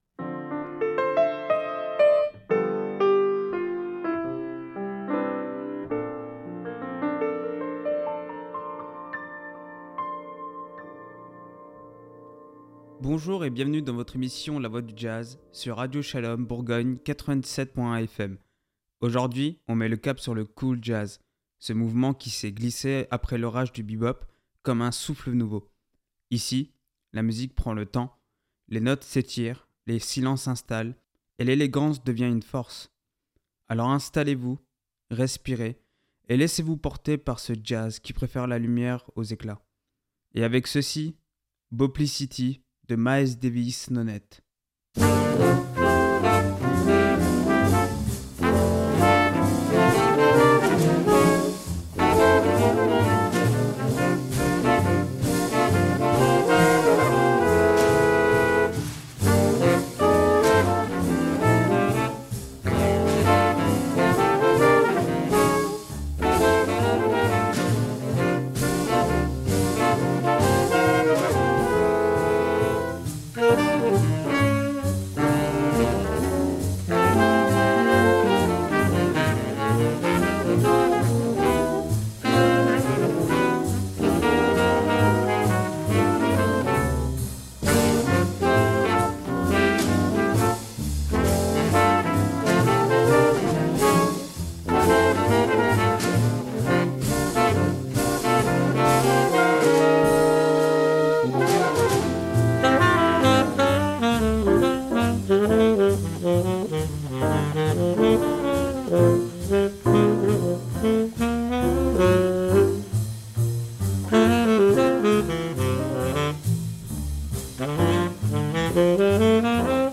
Plongez dans l’univers feutré du Cool Jazz, ce courant qui a su apporter élégance et subtilité après les tempêtes du bebop.
Découvrez comment chaque note prend son temps, comment chaque silence devient essentiel, et comment le Cool Jazz transforme l’écoute en une expérience méditative et lumineuse.